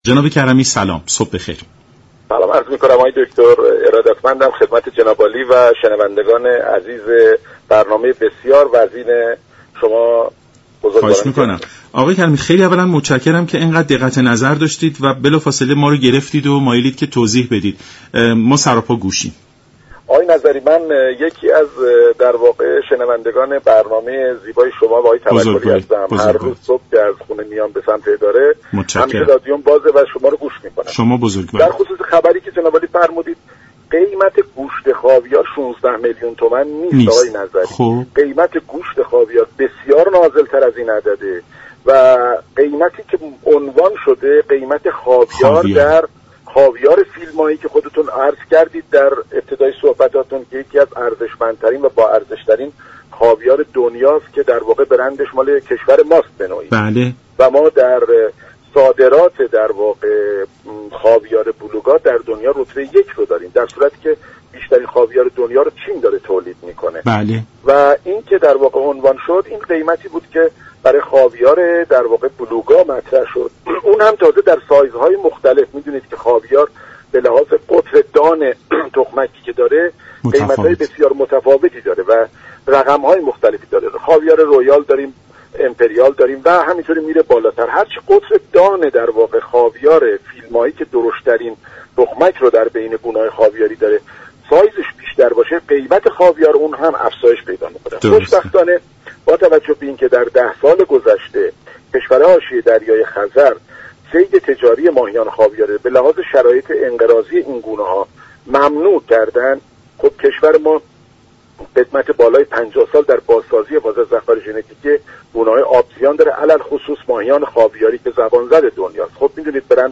دریافت فایل به گزارش شبكه رادیویی ایران، «ناصر كرمی راد» مدیر كل دفتر بازسازی و حفاظت از ذخایر ژنتیكی آبزیان در برنامه «سلام صبح بخیر» درباره قیمت گوشت خاویار گفت: قیمت گوشت خاویار 16 میلیون تومان نیست و این قیمت تنها برای گوشت خاویار بلوگا است.